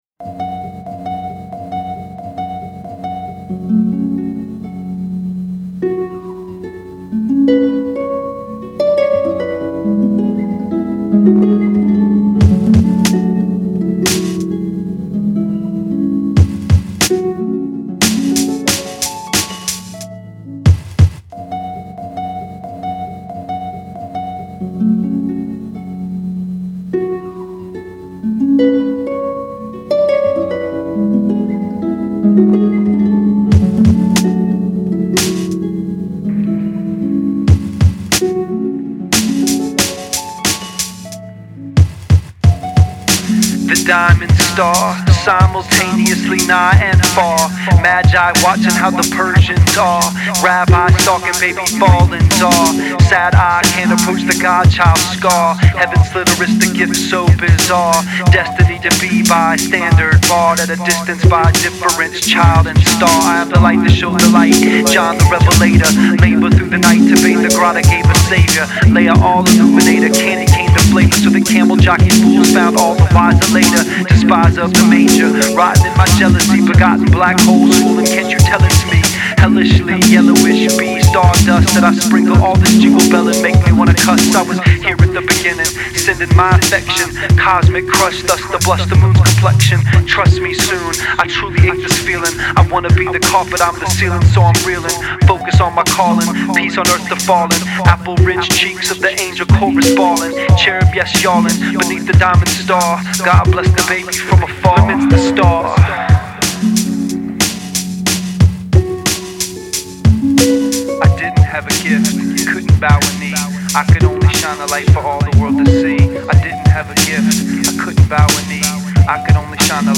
Rap news on deck.